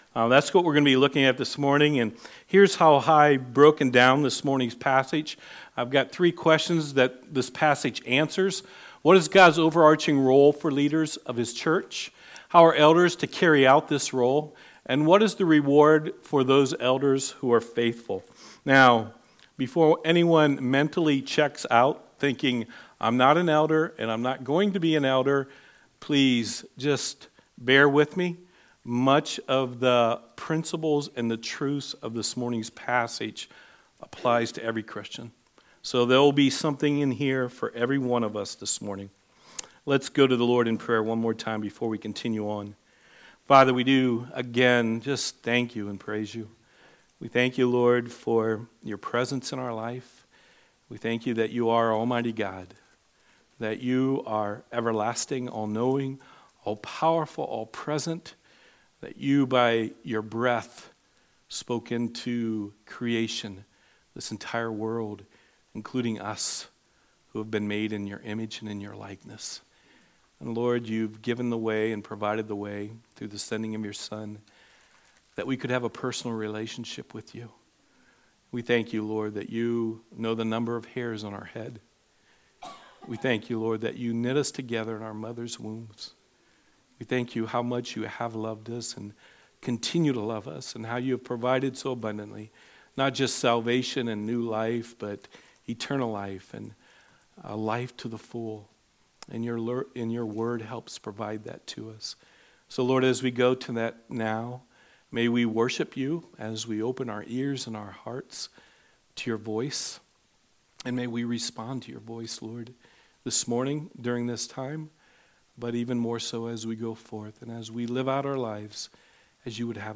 There were some recording issues during the first portion of this message. It picks up a few minutes into the sermon.